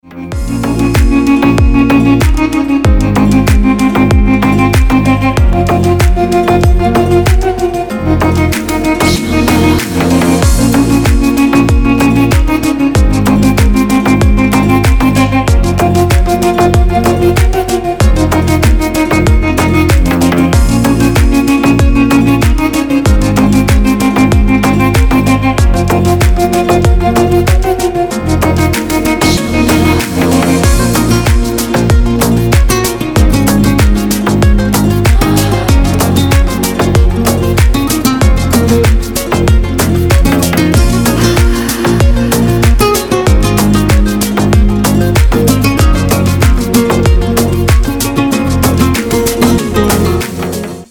Красивый рингтон без слов